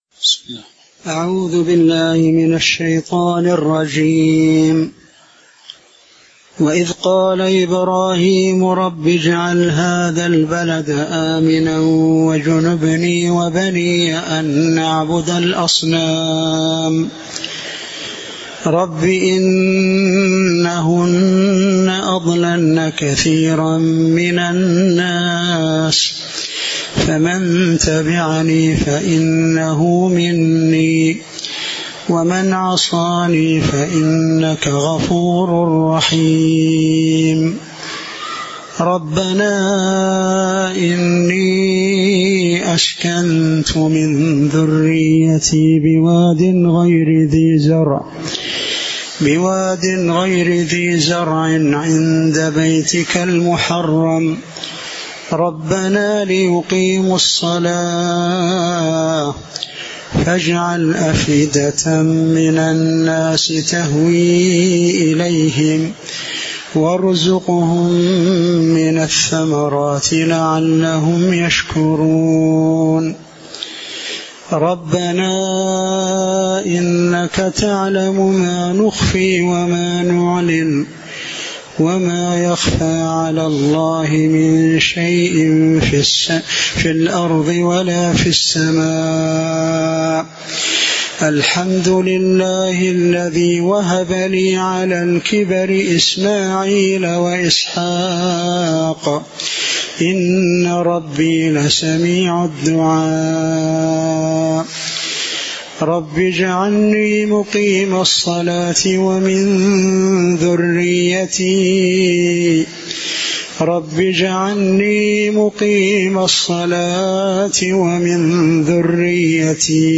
تاريخ النشر ٦ جمادى الأولى ١٤٤٦ هـ المكان: المسجد النبوي الشيخ